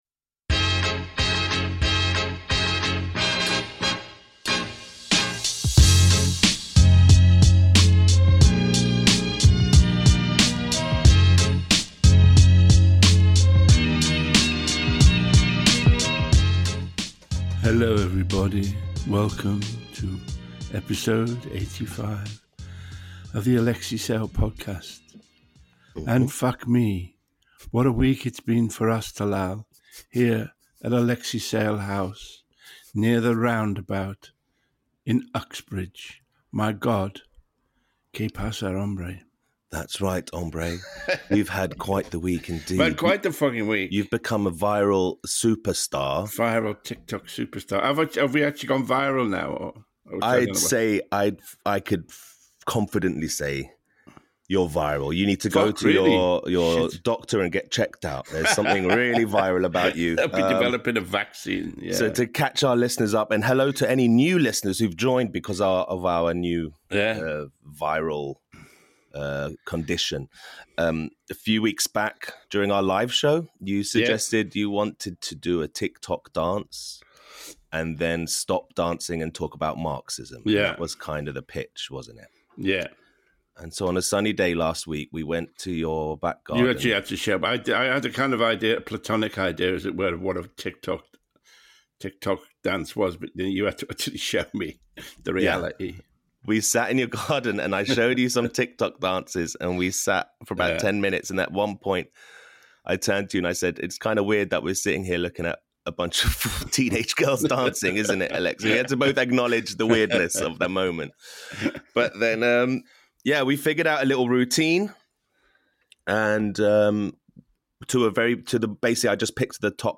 This live show was performed at the Museum of Comedy, London on Thursday 24th April 2025.